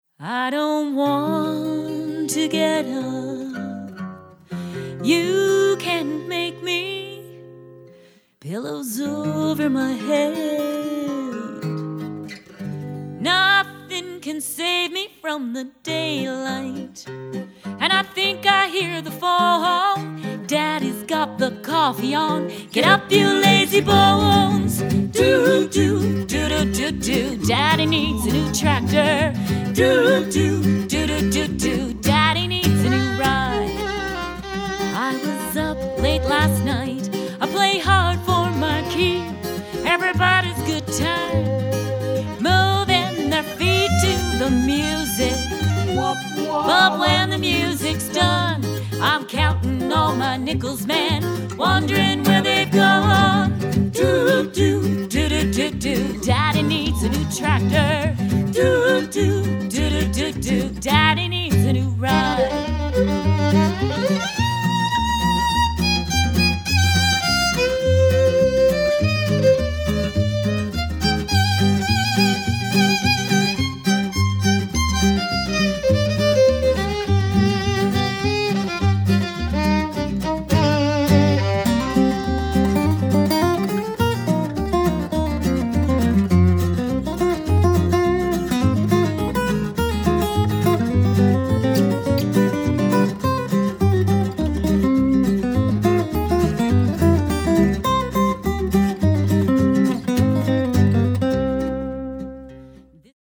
lead & harmony vocals, guitars
violin